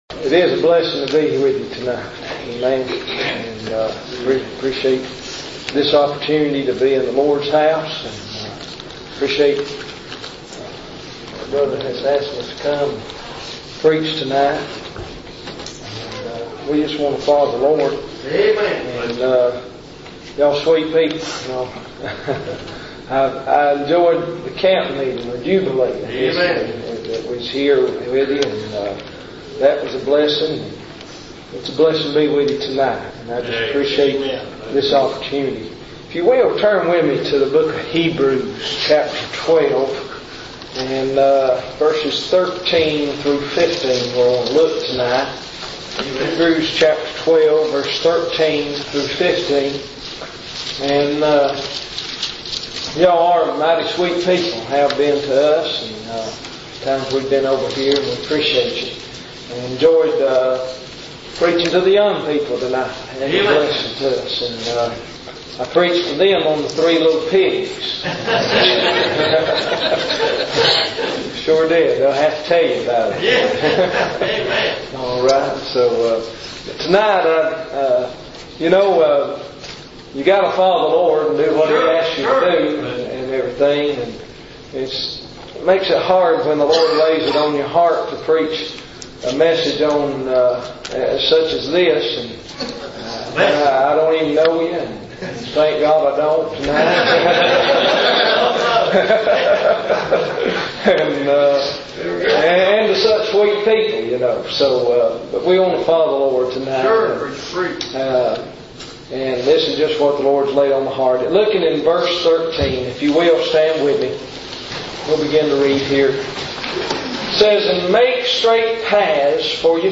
Preached At Truth September 10, 2006 6PM.
thesinofbitterness4908philadelphiabaptist.mp3